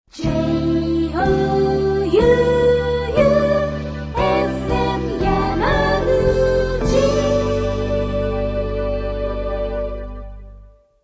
5秒タイプの前に「ＪＯＵＵ」というコールサインが付いてます。
※若干音質は抑えめです